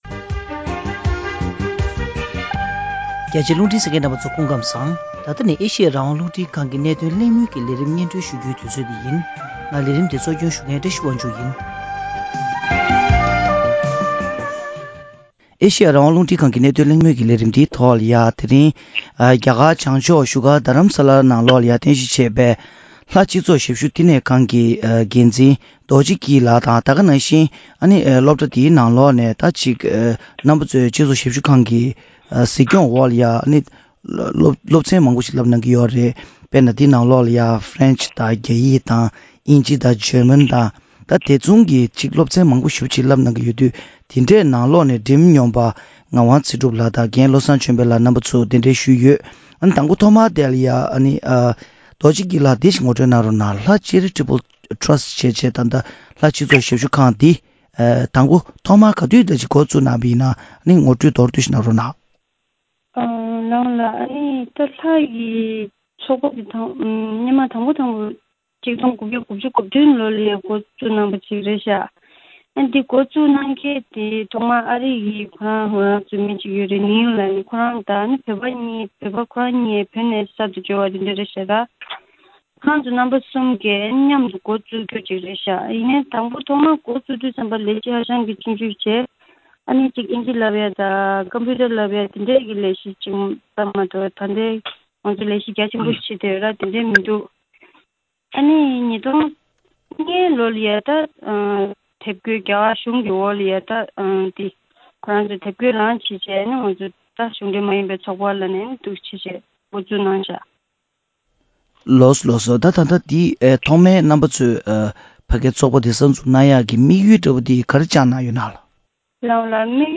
བཞུགས་སྒར་རྡ་རམ་ས་ལར་རྟེན་གཞི་བྱས་པའི་ལྷ་སྤྱི་ཚོགས་ཞབས་ཞུ་ཁང་གི་འདས་པའི་ལོ་ ༢༠ ལྷག་གི་ཞབས་ཞུའི་ལས་དོན་ལ་ཕྱི་མིག་སྐྱར་ཞིབ་སླད་གླེང་མོལ་ཞུས་པ།